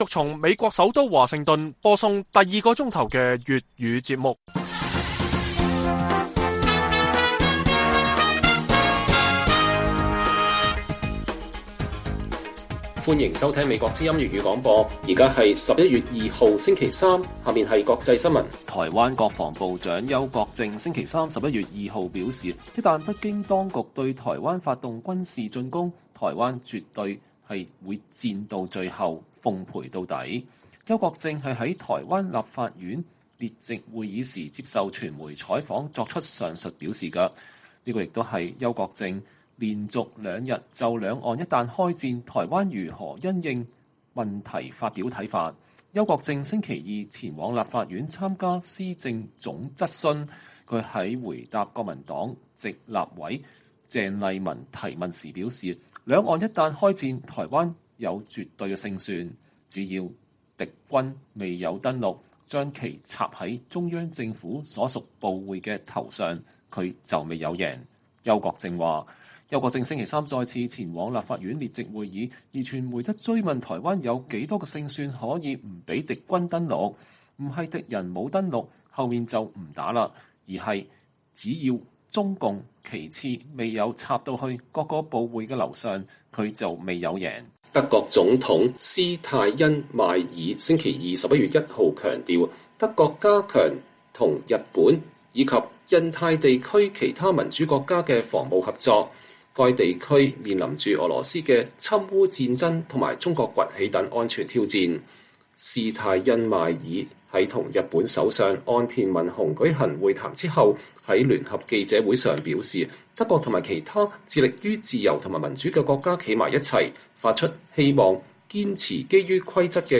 粵語新聞 晚上10-11點: 台灣防長：兩岸開戰台灣有勝算，國軍將“奉陪到底”